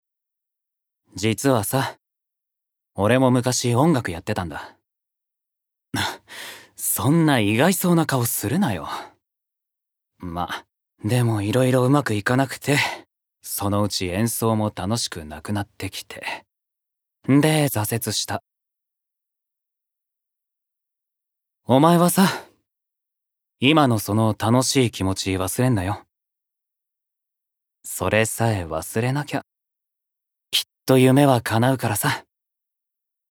Voice Sample
ボイスサンプル
セリフ１